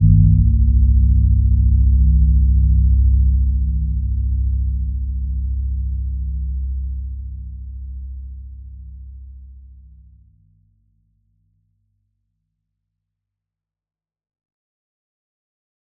Gentle-Metallic-2-C2-p.wav